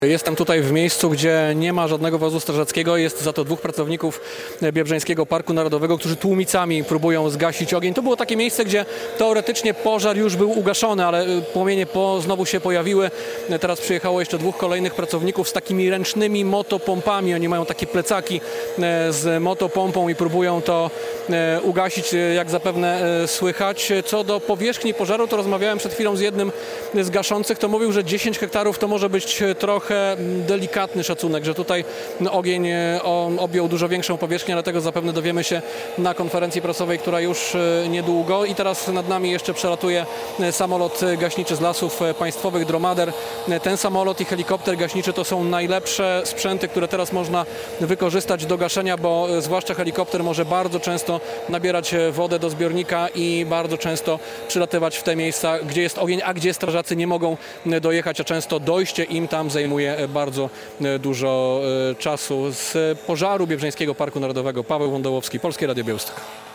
Pożar w Biebrzańskim Parku Narodowym - relacja